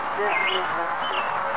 EVP (Electronic Voice Phenomena)
Some examples of EVP recordings (in wav format)